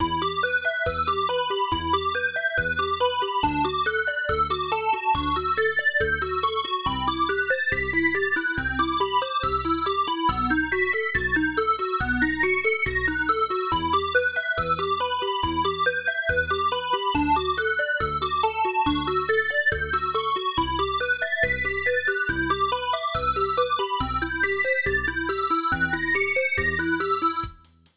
Create a retro 8-bit game soundtrack: 16 bars at 140 BPM in E minor with a square-wave lead melody, arpeggiated chords, and a simple bass line.
Result: Song restructured into AABB form — measures duplicated and rearranged
8-bit-game-soundtrack_measures.wav